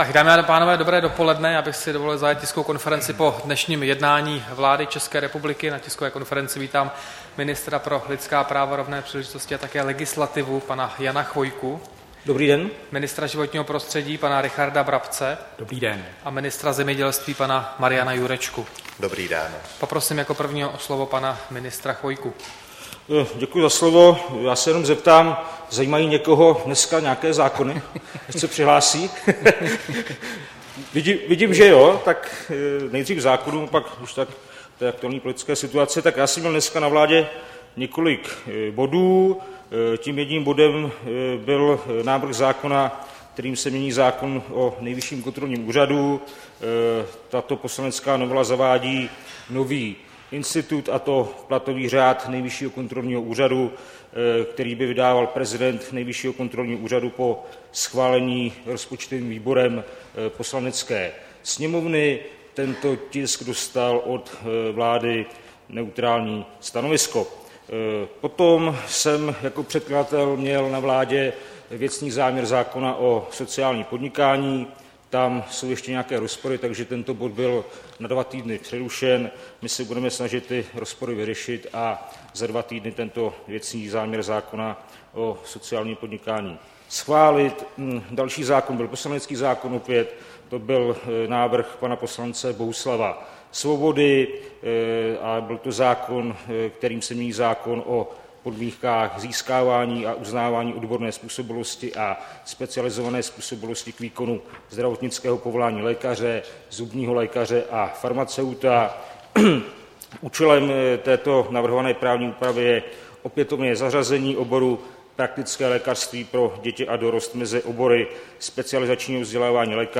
Tisková konference po jednání vlády, 3. května 2017